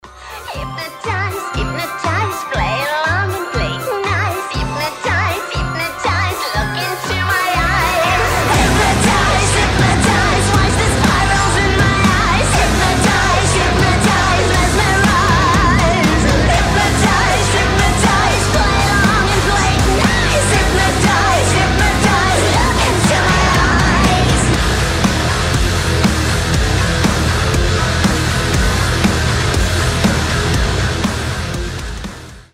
• Качество: 320, Stereo
громкие
Драйвовые
женский вокал
веселые
нарастающие
Metal